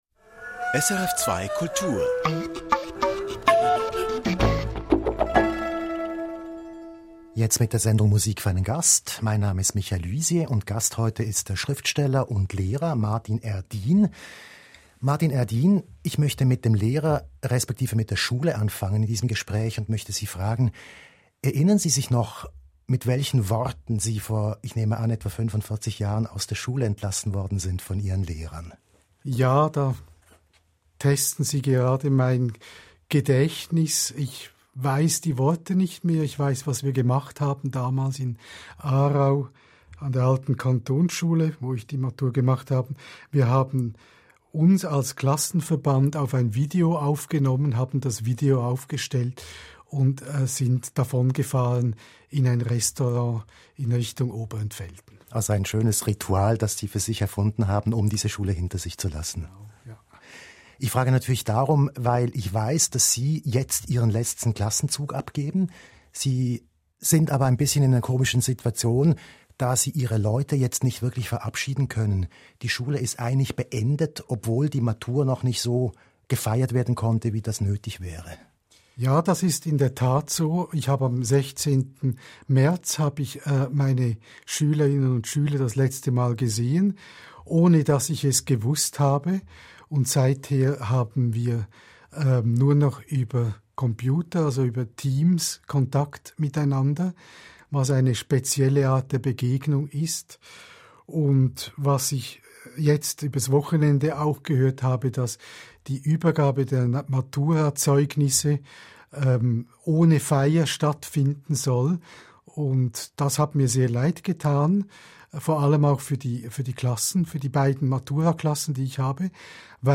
Maturrede von Martin R. Dean